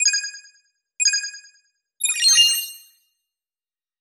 warning.mp3